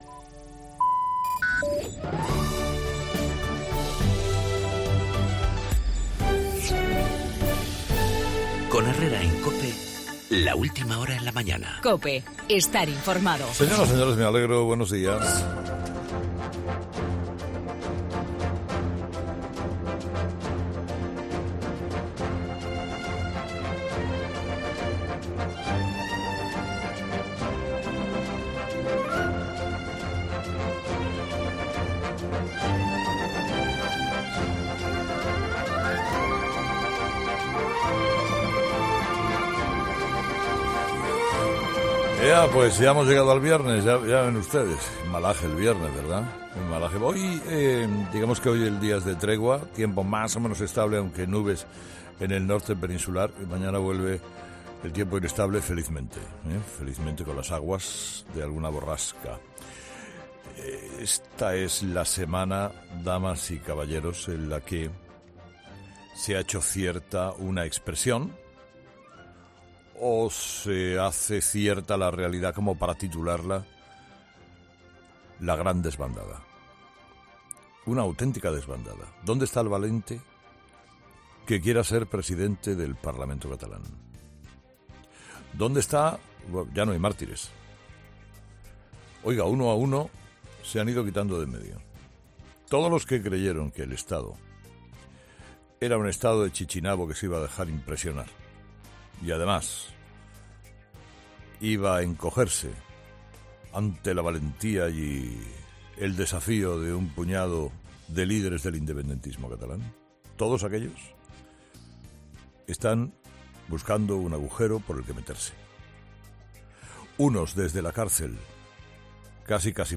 Monólogo de las 8 de Herrera 'Herrera a las 8'